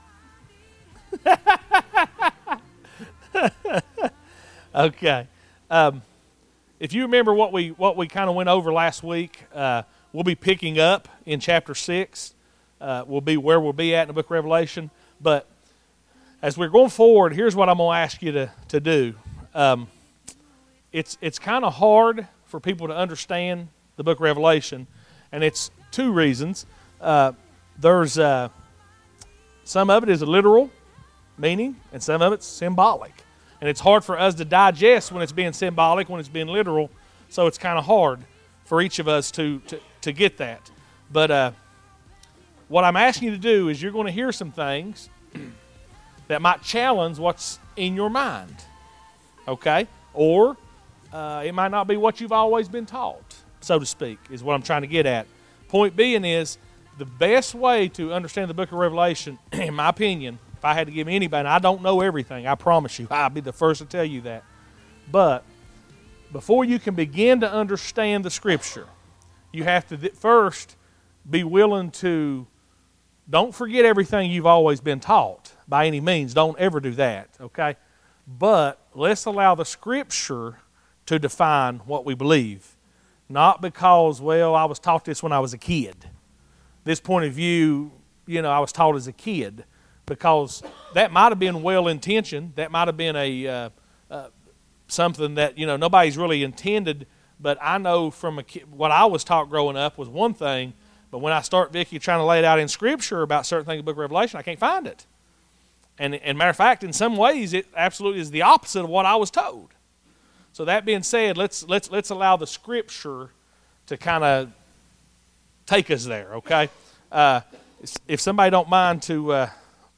Summer Prophecy Series Salvation and The Tribulation Wednesday